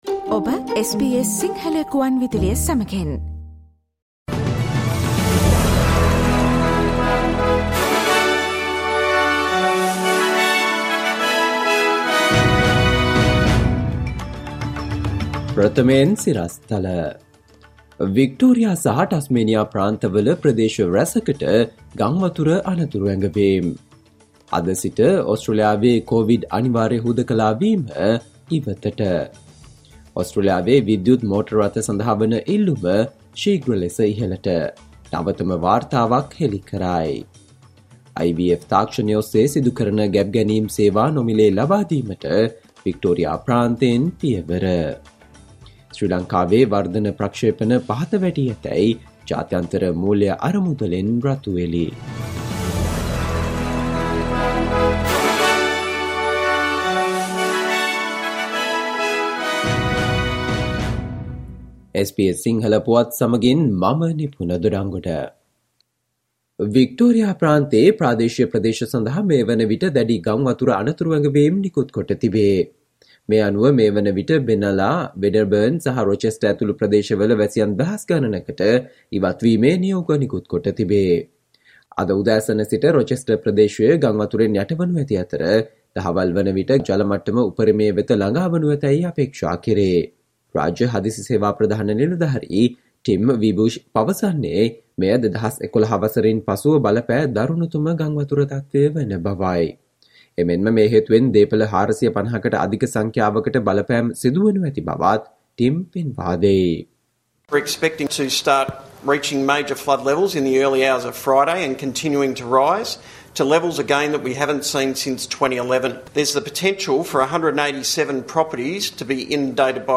Listen to the SBS Sinhala Radio news bulletin on Friday 14 October 2022